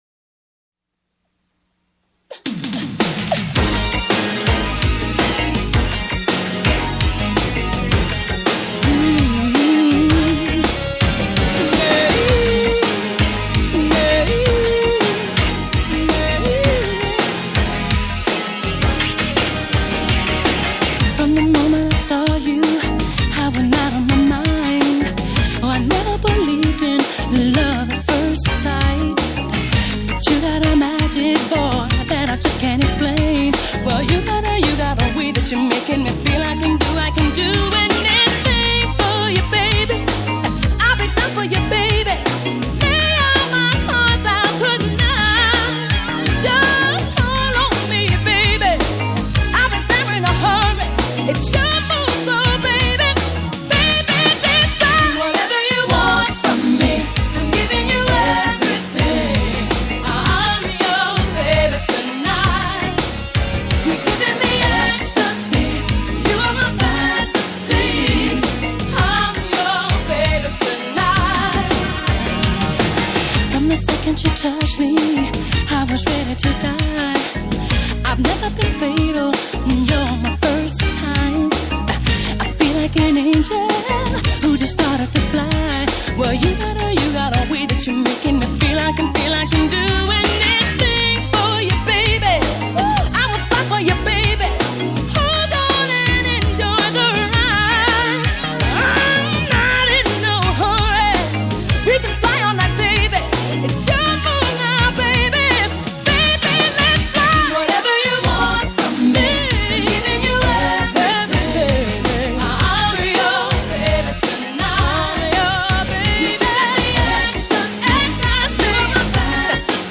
Pop, R&B, New Jack Swing